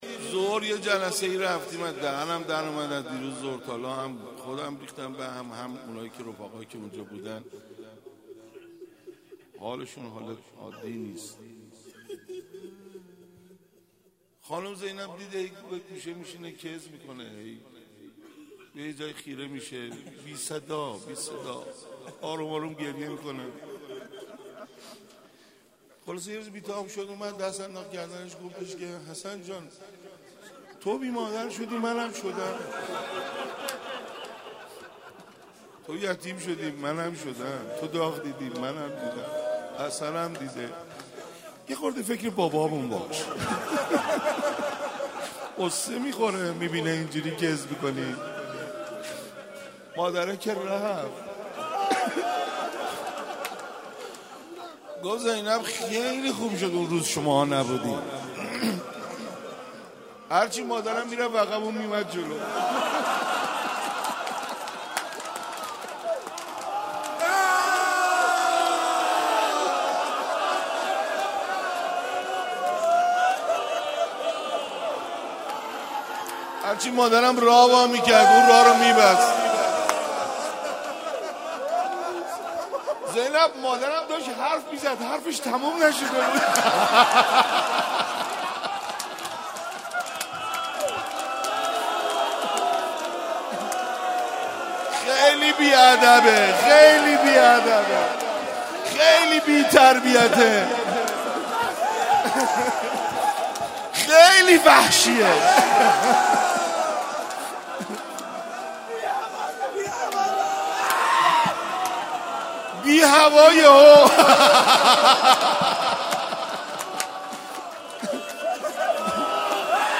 شب سوم رمضان 95